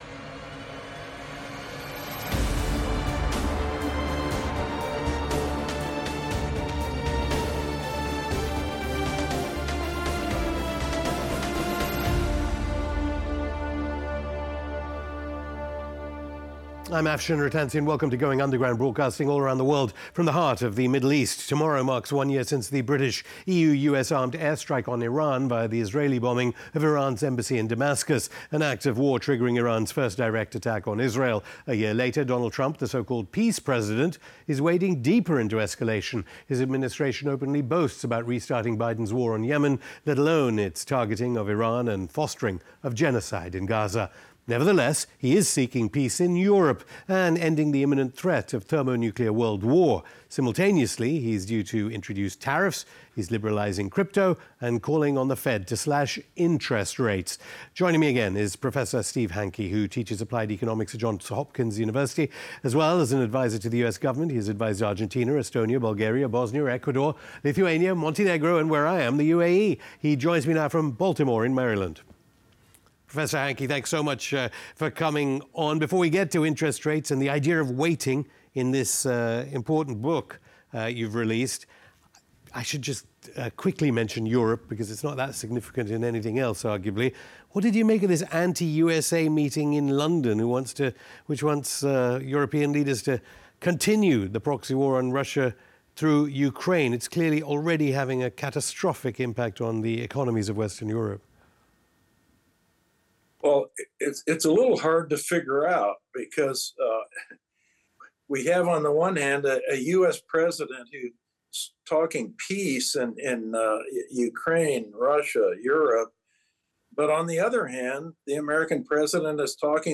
Hosted by Afshin Rattansi